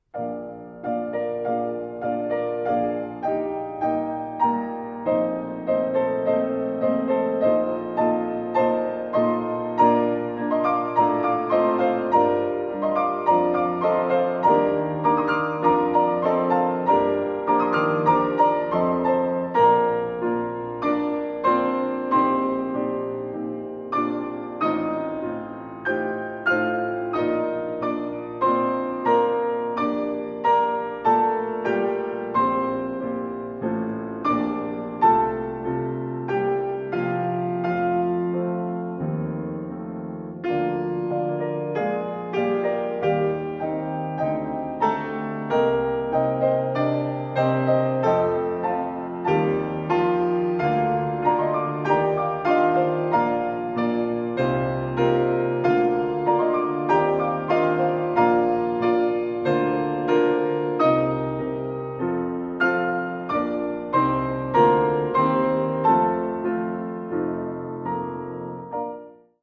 Choraltranskriptionen und Bearbeitungen für 2 Klaviere
Oktober 2016, Lisztzentrum Raiding
Klaviere: Steinway